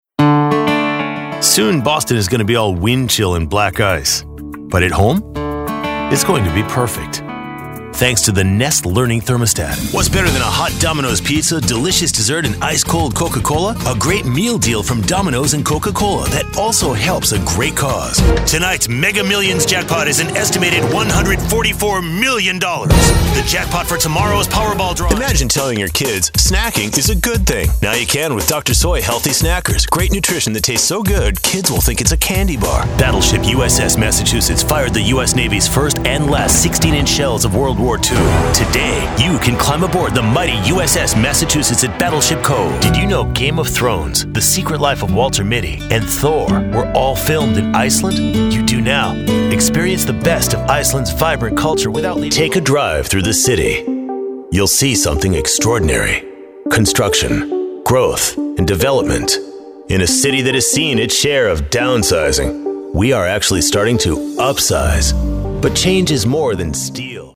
Dynamic Voice Talent ✨ for all media